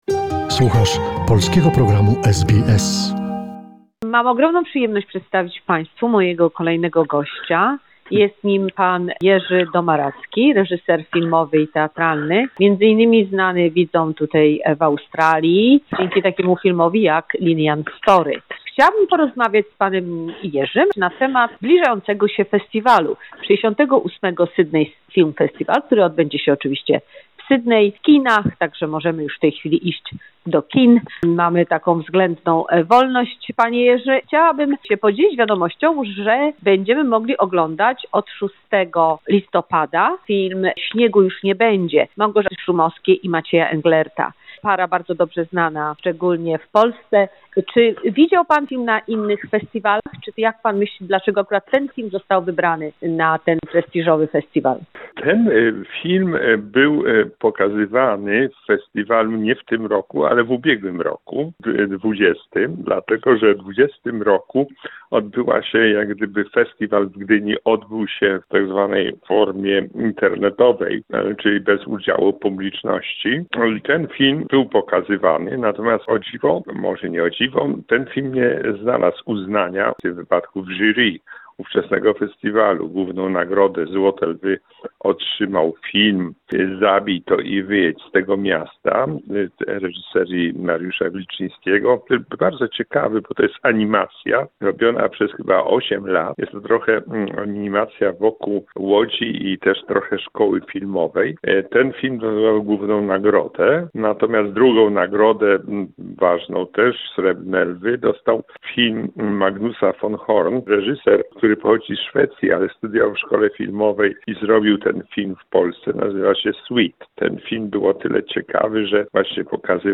Rozmowa z reżyserem filmowym Jerzym Domaradzkim